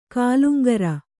♪ kāluŋgara